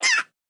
Player Hit.wav